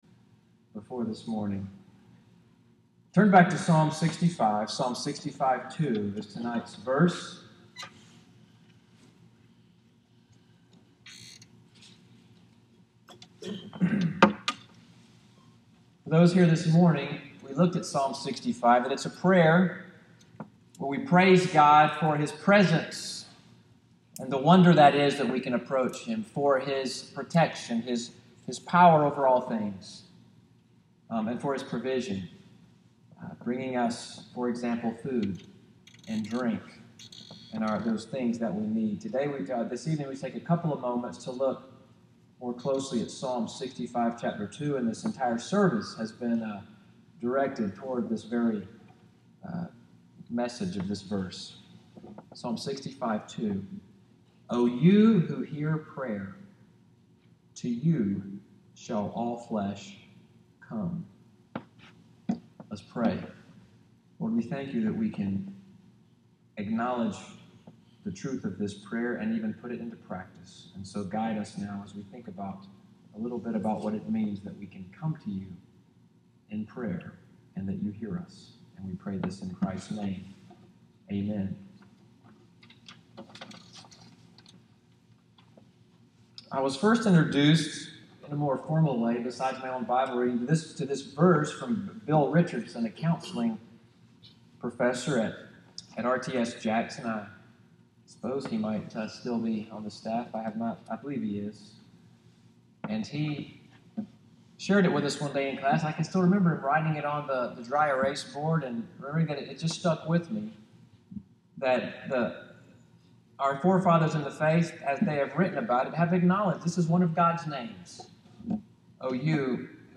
EVENING WORSHIP at NCPC, audio from the sermon, "You Who Hear Prayer," October 2, 2016. Sermon text: Psalm 65:2